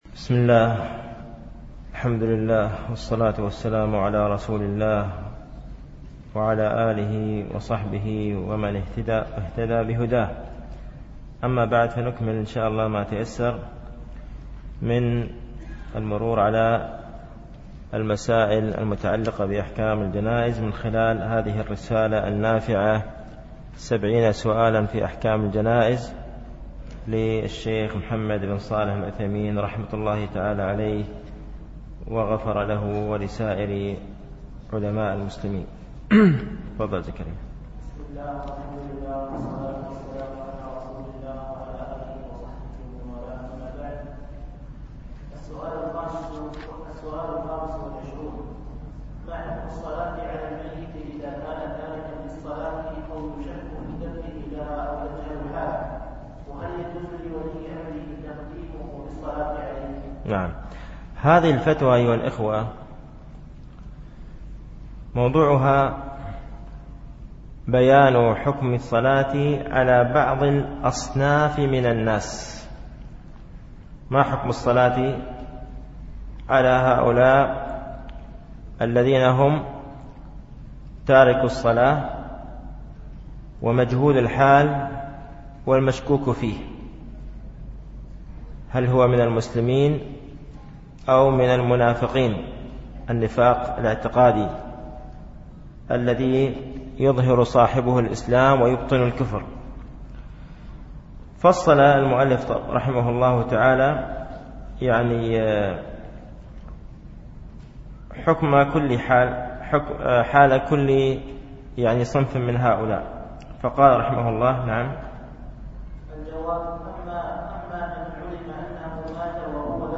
MP3 Mono 22kHz 32Kbps (CBR)